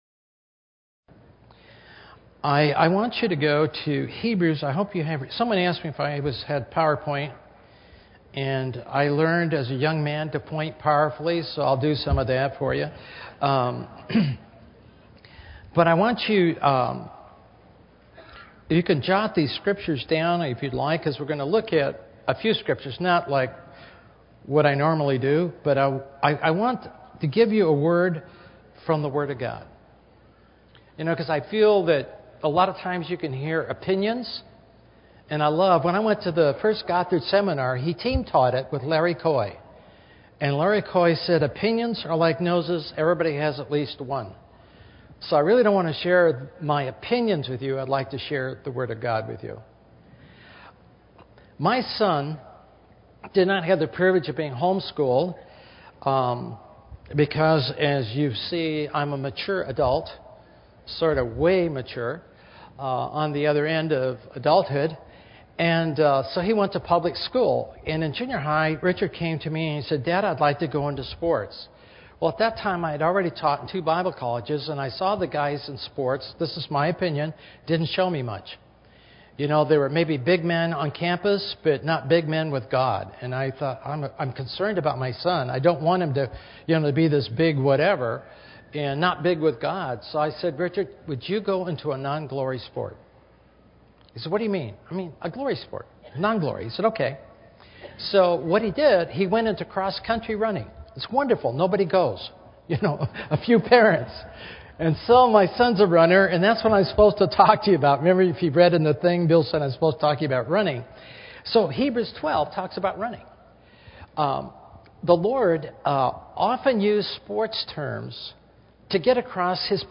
In this sermon, the speaker emphasizes the importance of keeping our focus on Christ amidst the distractions and attacks of the enemy. He uses the analogy of running a race, where we are to gaze at Christ but also glance at the obstacles along the way.